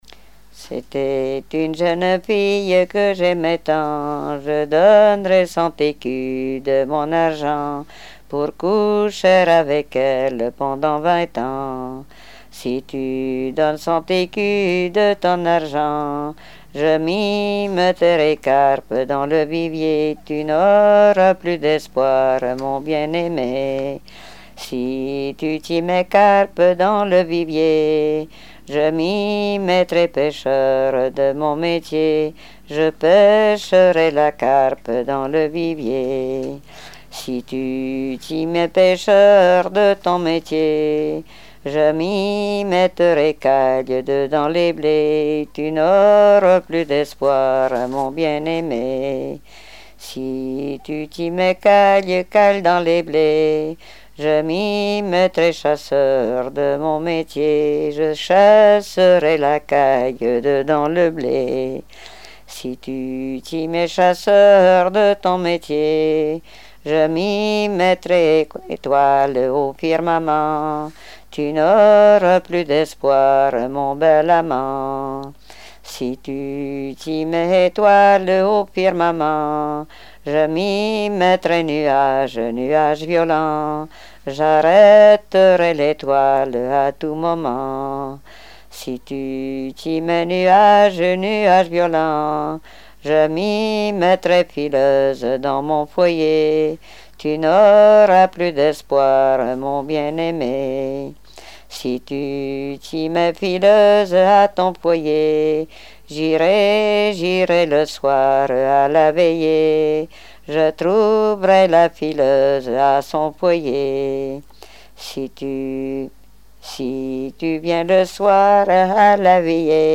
Répertoire de chansons traditionnelles et populaires
Pièce musicale inédite